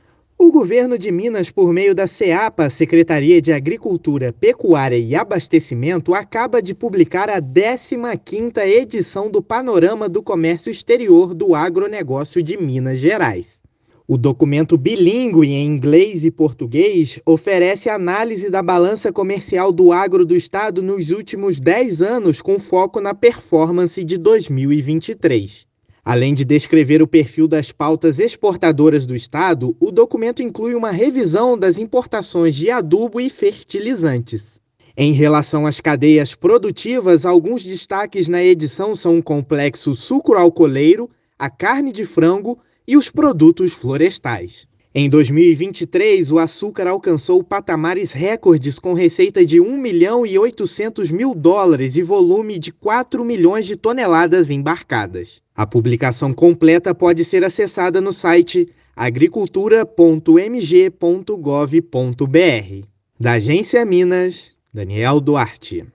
Documento bilíngue divulgado pela Seapa apresenta balança comercial, principais destinos e destaca o potencial exportador dos produtos agropecuários do estado. Ouça a matéria de rádio: